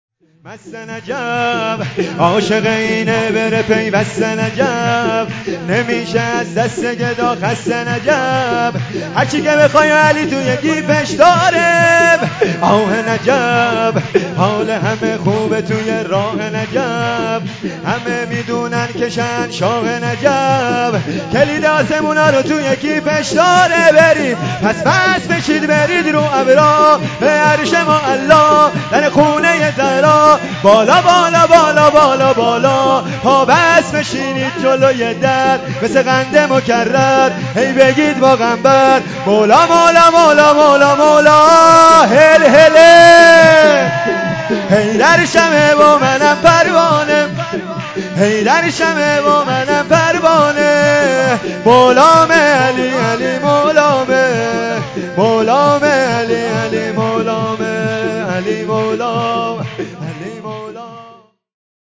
ولادت حضرت علی(ع) و ولادت حضرت جوادالائمه(ع) و ولادت حضرت علی اصغر(ع) و روز پدر 1403